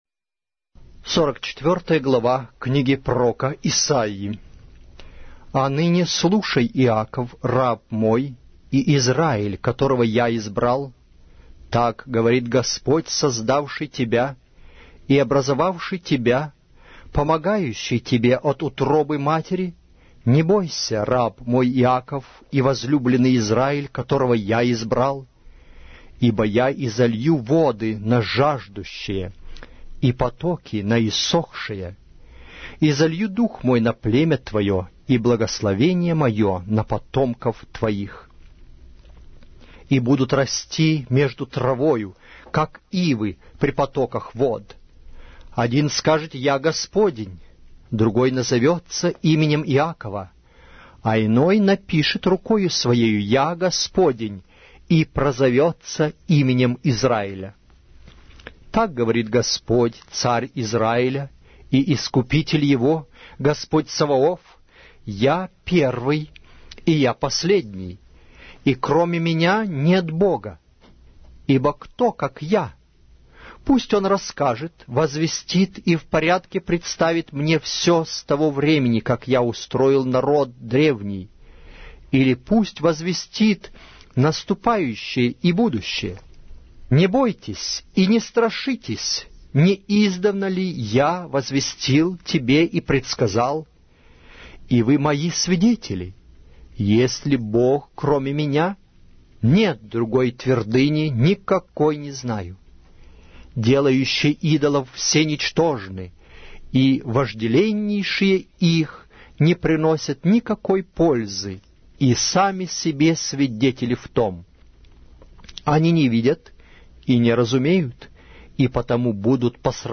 Аудиокнига: Пророк Исаия